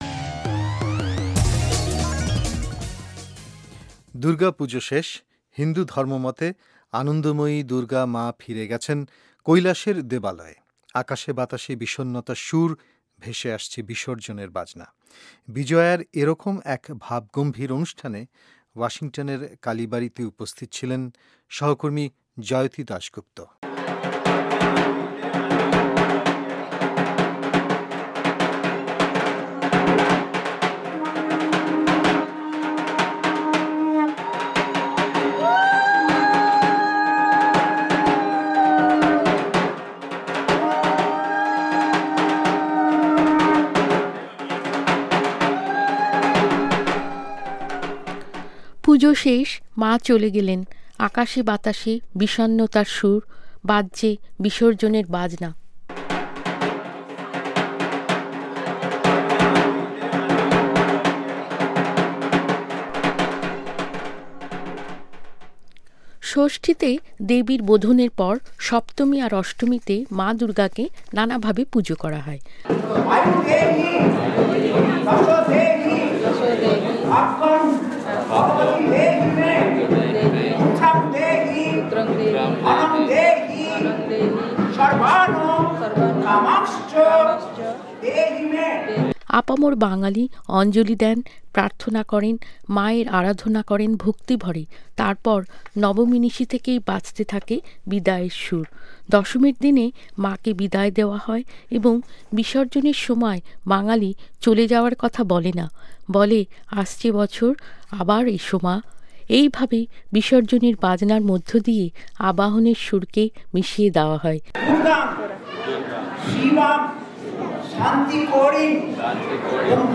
মা চলে গেলেন, আকাশে–বাতাসে বিষণ্ণতার সুর, বাজছে বিসর্জনের বাজনা।
আকাশ জুরে হালকা নীলের খেলা, কিন্তু এর মধ্যে আগমনীর সুর রূপ নিয়েছে বিসর্জনের ঢাকের বাজনায়।